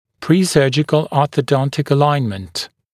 [ˌpriː’sɜːʤɪkl ˌɔːθə’dɔntɪk ə’laɪnmənt][ˌпри:’сё:джикл ˌо:сэ’донтик э’лайнмэнт]дохирургическое ортодонтическое выравнивание